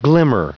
Prononciation du mot glimmer en anglais (fichier audio)
Prononciation du mot : glimmer